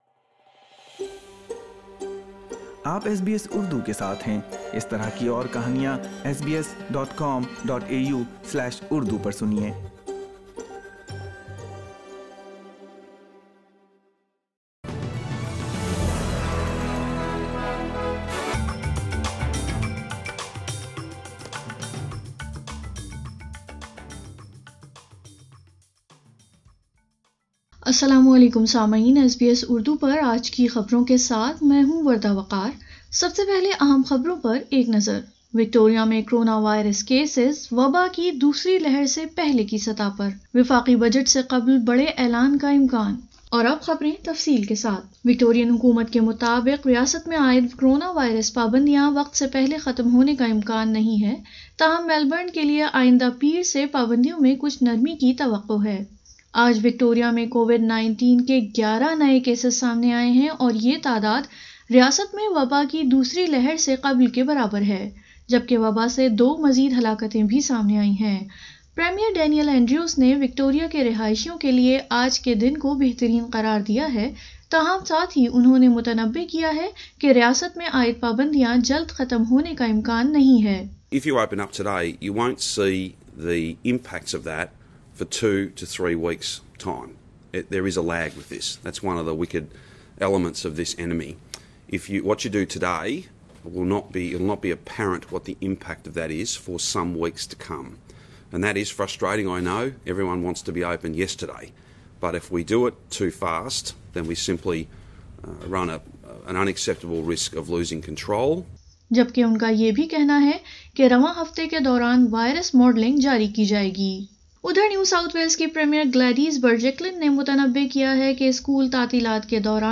اردو خبریں 21 ستمبر 2020